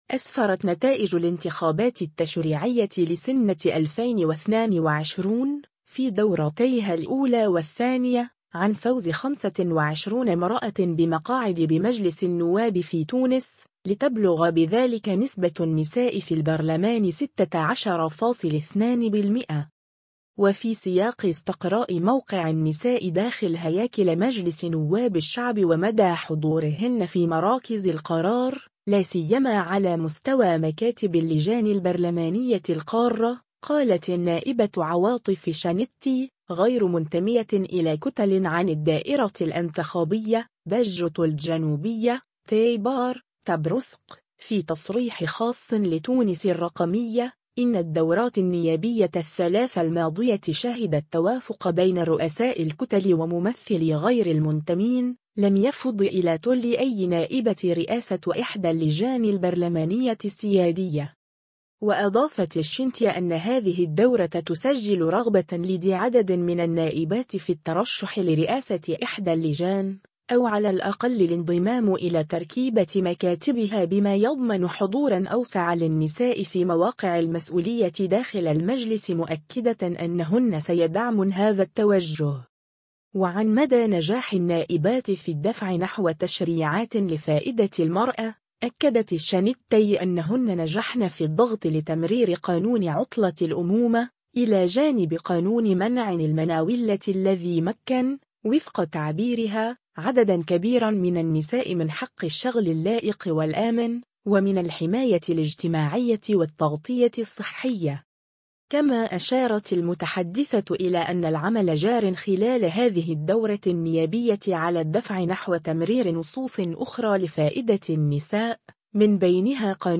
وفي سياق استقراء موقع النساء داخل هياكل مجلس نواب الشعب ومدى حضورهن في مراكز القرار، لا سيما على مستوى مكاتب اللجان البرلمانية القارة، قالت النائبة عواطف الشنتي (غير منتمية إلى كتل)،عن الدائرة الإنتخابية: باجة الجنوبية – تيبار – تبرسق، في تصريح خاص لـ“تونس الرقمية”، إن الدورات النيابية الثلاث الماضية شهدت “توافقًا” بين رؤساء الكتل وممثلي غير المنتمين، لم يفضِ إلى تولّي أي نائبة رئاسة إحدى اللجان البرلمانية السيادية.